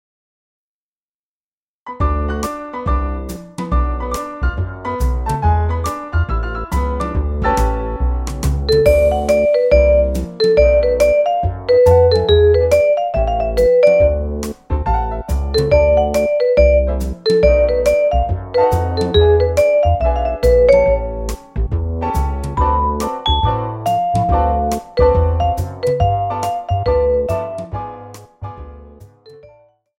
Recueil pour Violoncelle